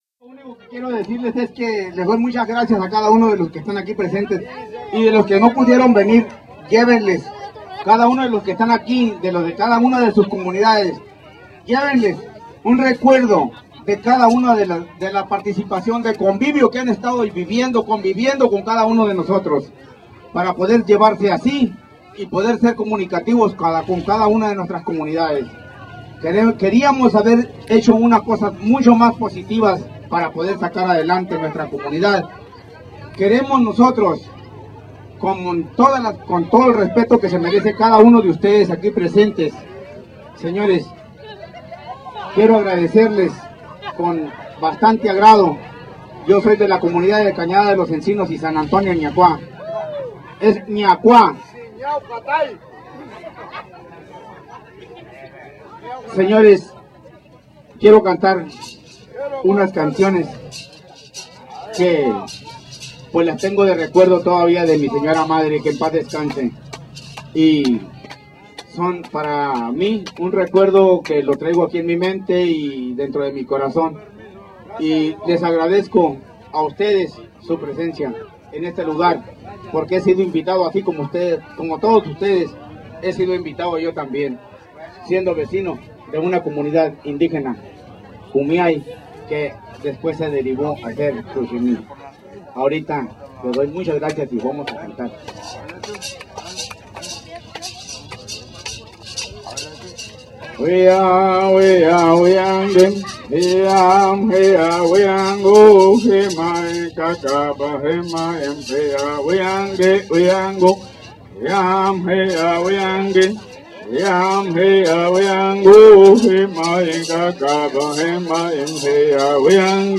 Pictures and movie video audio recordings from the annual Kumeyaay Fiesta in San Jose de la Zorra, March 19, 2006, feature many southern Kumeyaay, Cocopah and Cucapa bird singers singing and bird dancers dancing, including two large Indian bonfires for light and warmth, a free hot feast and friendship for all who attended.
CULTURAL SINGER
Yuman bird singer
playing a traditional California Indian gourd rattle musical instrument